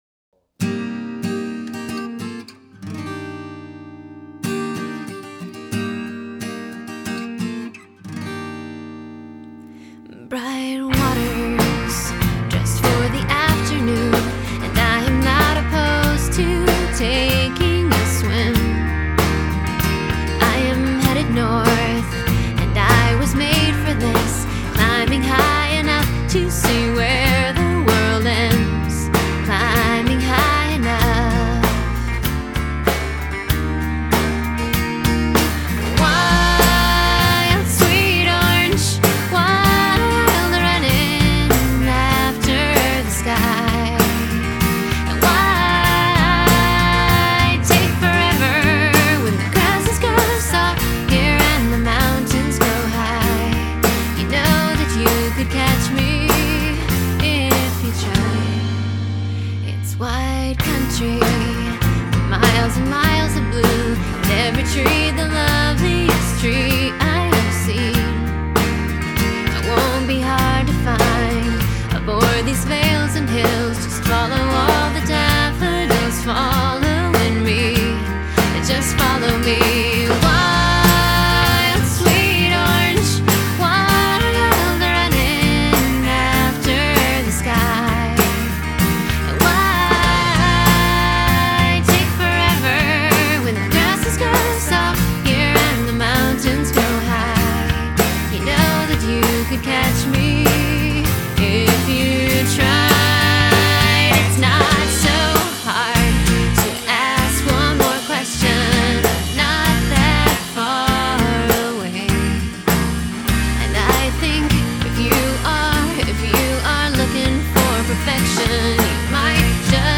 Oh and yes, it's me singing and playing guitar.